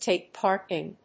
アクセントtàke párt in…